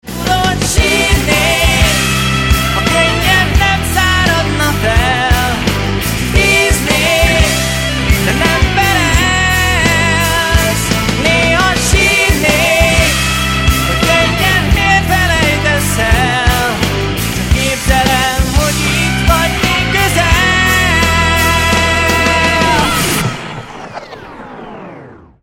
ének, vokál
basszusgitár
nagybőgő
zongora
gitárok
billentyűs hangszerek